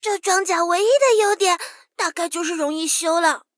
SU-76小破修理语音.OGG